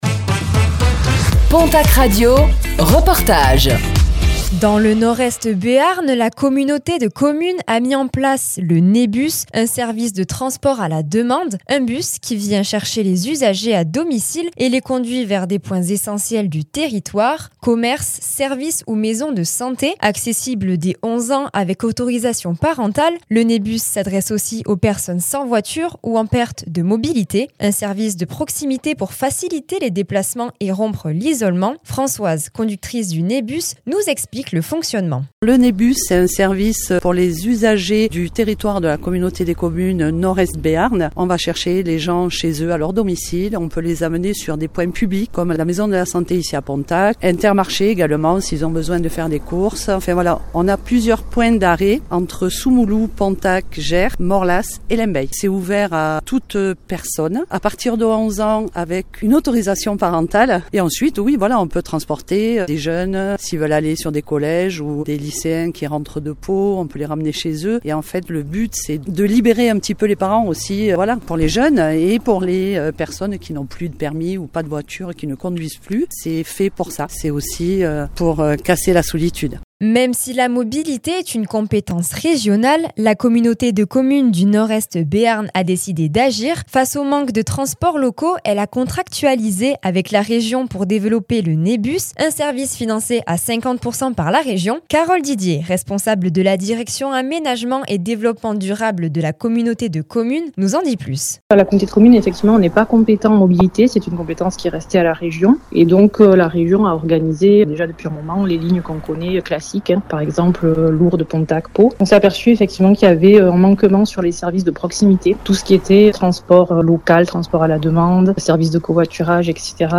NEBus : le bus à la demande qui facilite les déplacements dans le Nord-Est Béarn - Reportage du mercredi 04 février 2026